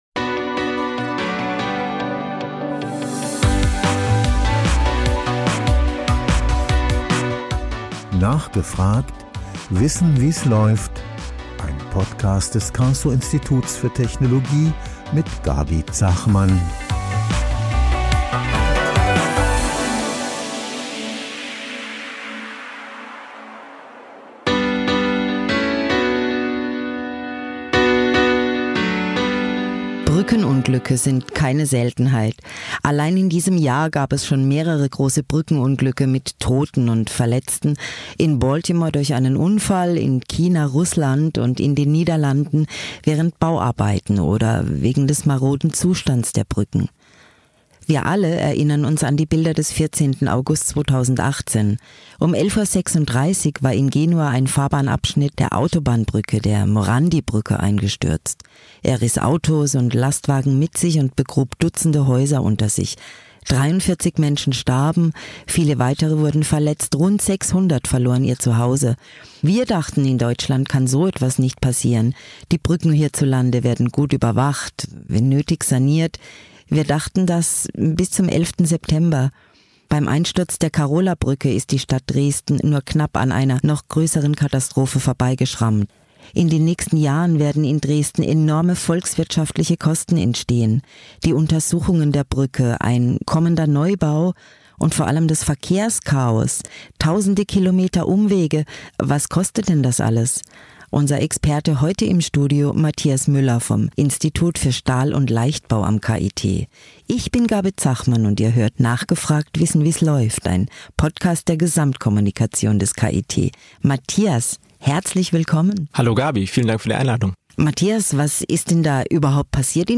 Im Interview-Podcast des Karlsruher Instituts für Technologie (KIT) sprechen unsere Moderatorinnen und Moderatoren mit jungen Forschenden, die für ihr Thema brennen.